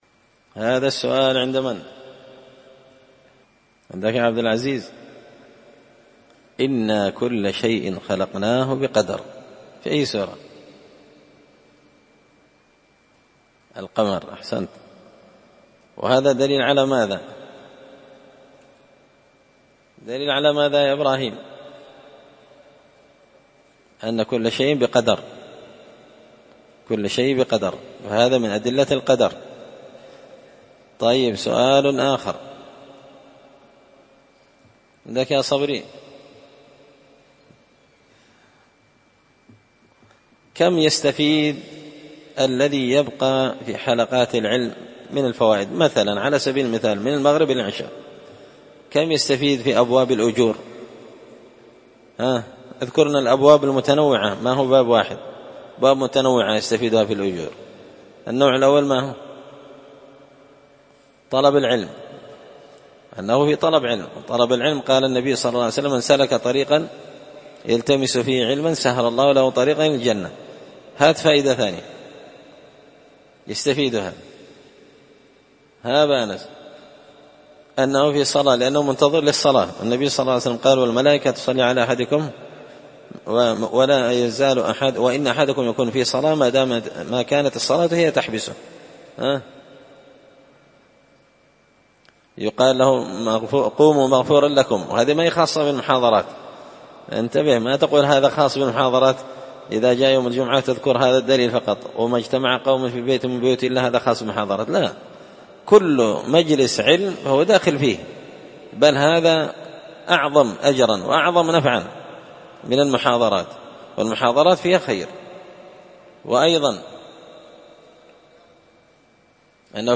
مذاكرة مع الطلاب حول فضل مجالس العلم
دار الحديث بمسجد الفرقان ـ قشن ـ المهرة ـ اليمن